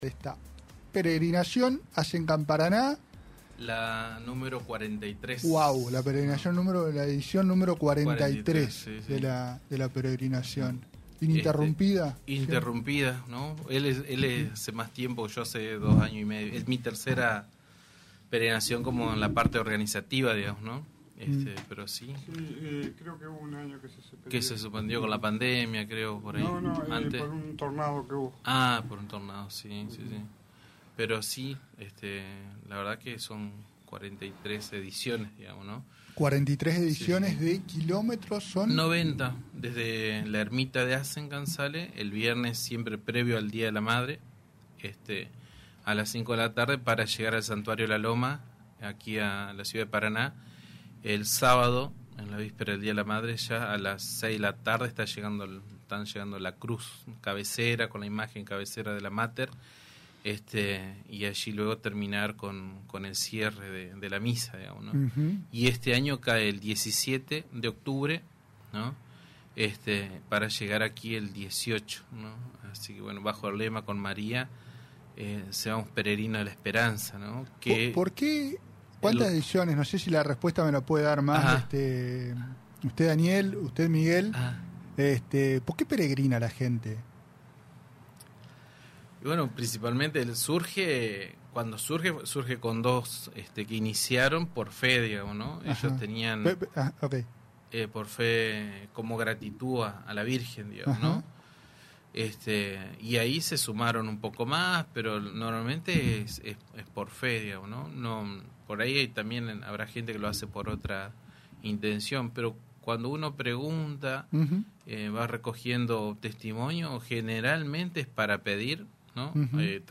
entrevista
realizada en los estudios de Radio Costa Paraná (88.1)